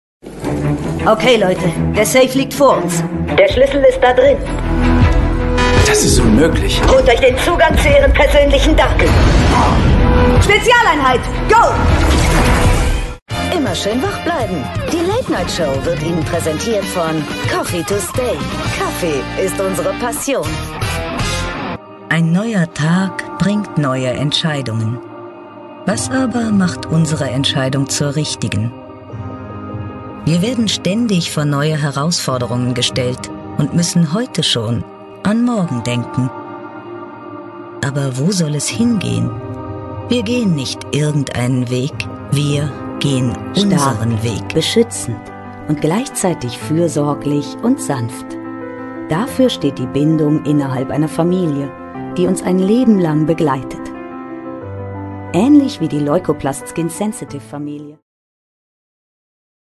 Polyvalente, Fiable, Chaude
Commercial
The sound of her voice is middle-aged (about 30-50 years), warm, dynamic and changeable.